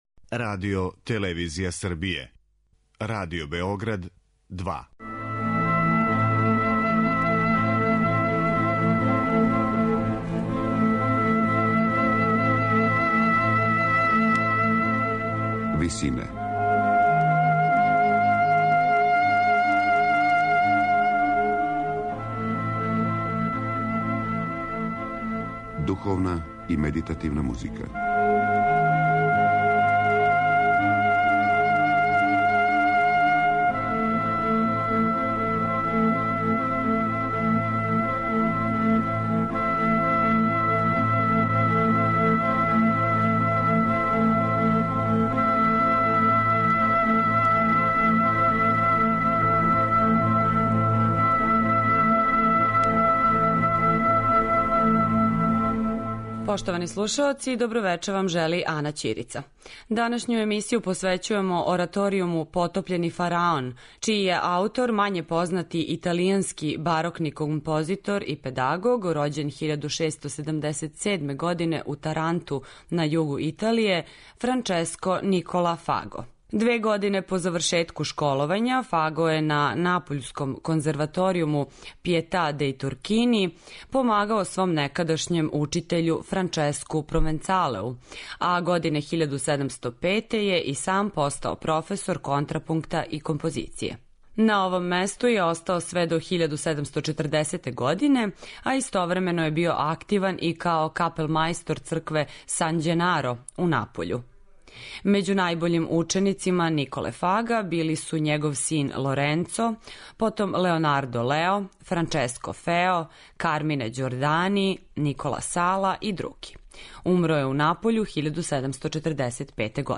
Одломци из ораторијума 'Потопљени фараон'
На крају програма, у ВИСИНАМА представљамо медитативне и духовне композиције аутора свих конфесија и епоха.